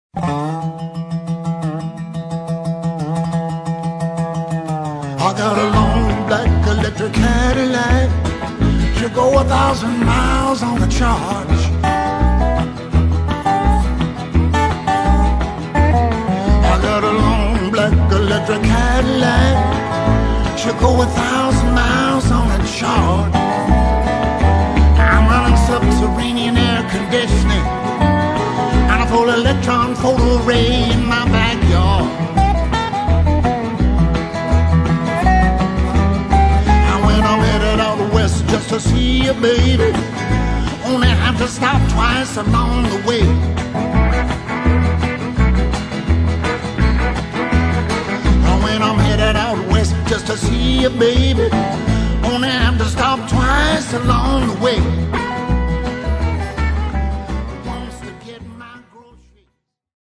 Dobro, Lap Steel, and Background Vocals
Bass, Tic-Tac Bass and String Arrangements
Acoustic and Electric Guitars
Violin and String Arrangements